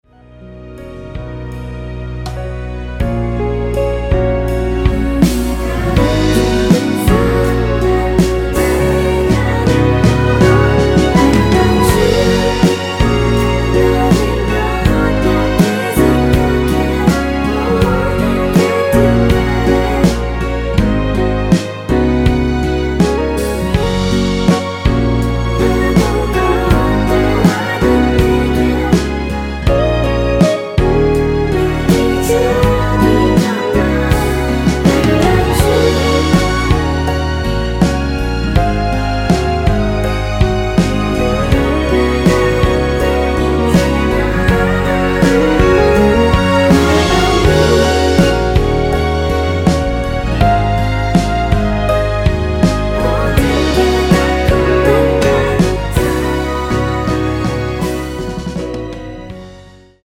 원키에서(+4)올린 코러스 포함된 MR입니다.(미리듣기 확인)
F#
앞부분30초, 뒷부분30초씩 편집해서 올려 드리고 있습니다.